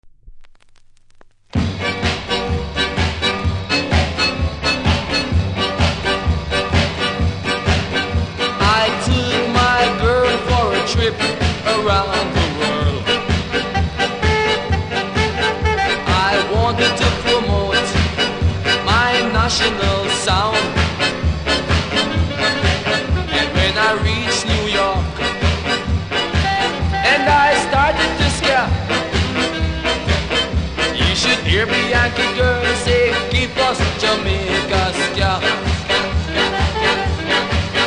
JAMAICA SKA 賛歌♪
多少キズありますが音は良好なので試聴で確認下さい。